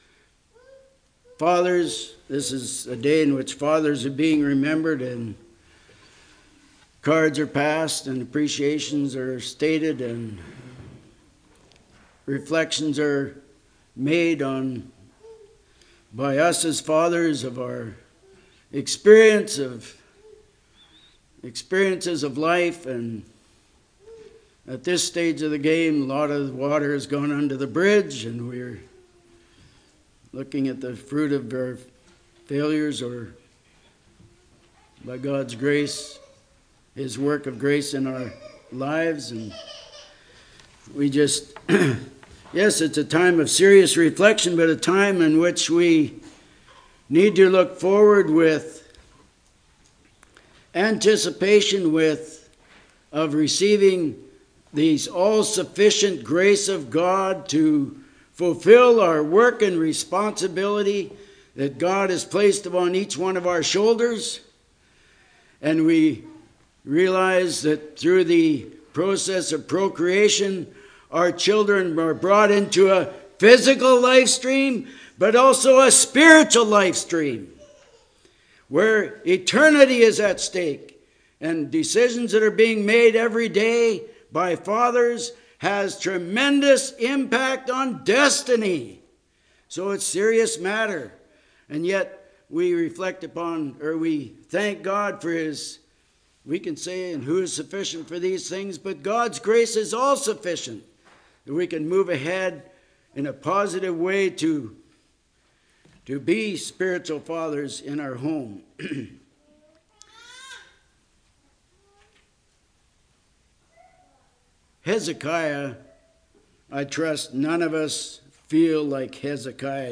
Sermons 06.18.23 Play Now Download to Device Ye Fathers Congregation